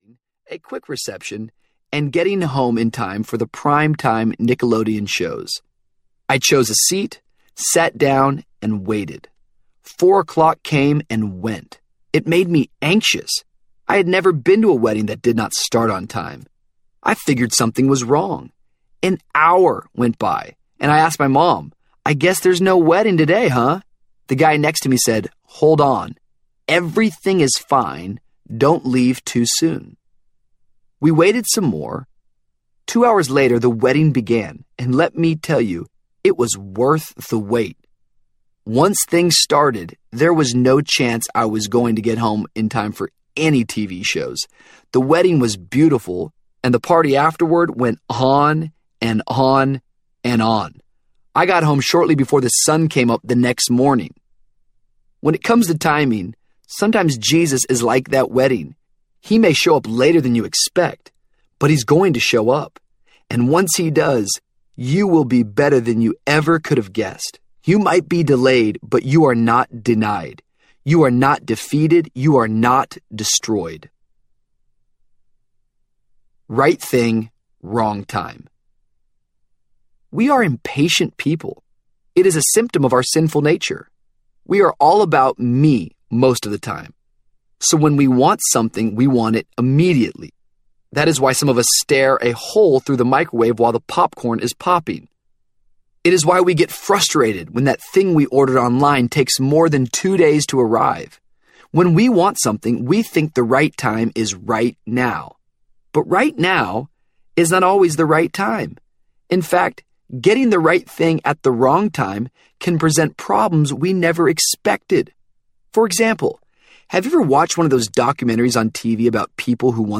Sandcastle Kings Audiobook
5.7 Hrs. – Unabridged